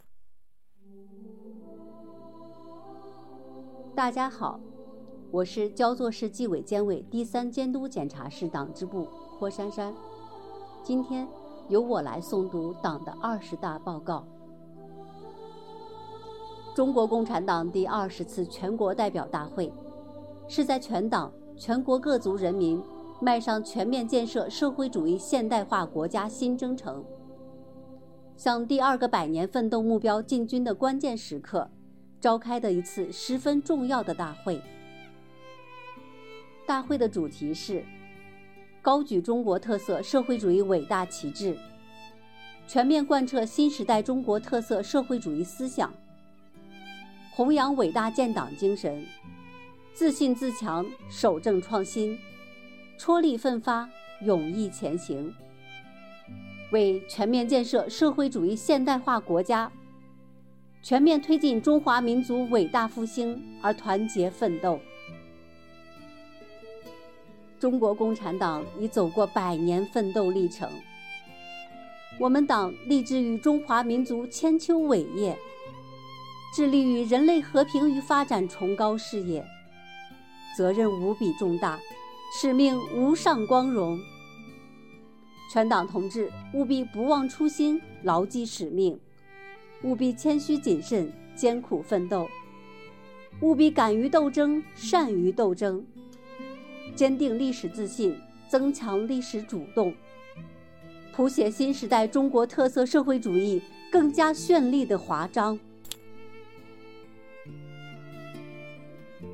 诵读内容第一期